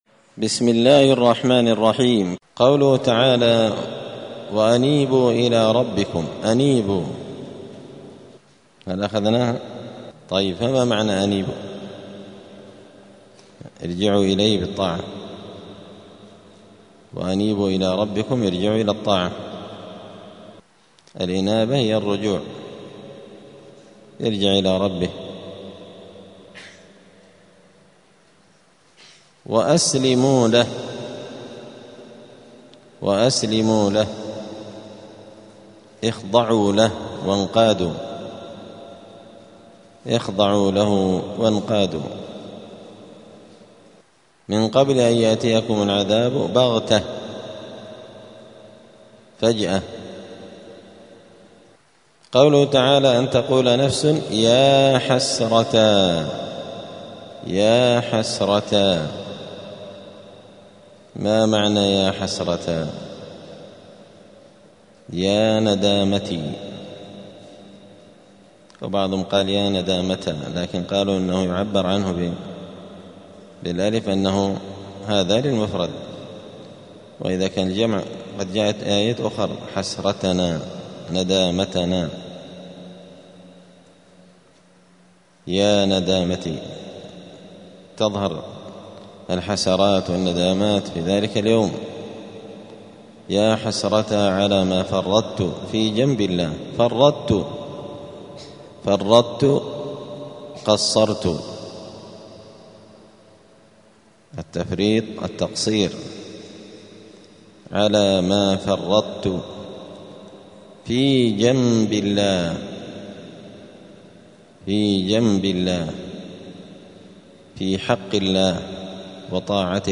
*(جزء الزمر سورة الزمر الدرس 259)*